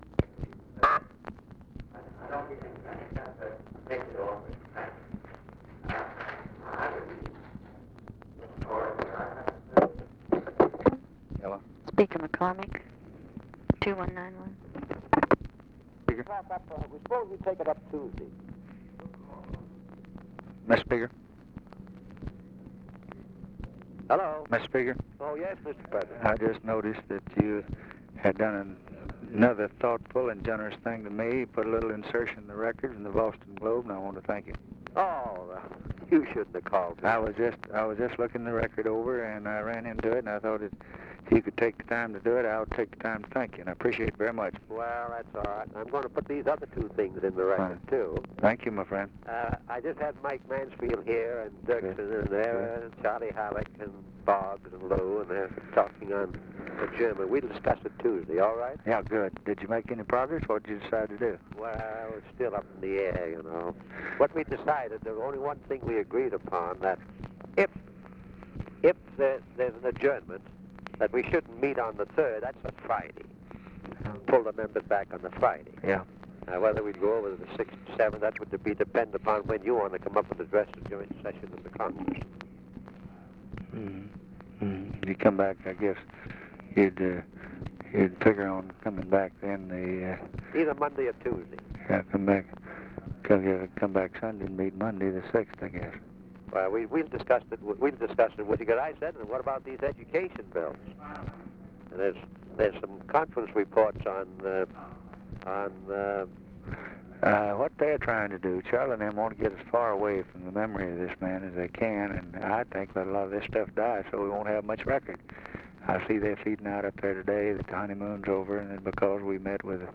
Conversation with JOHN MCCORMACK, December 5, 1963
Secret White House Tapes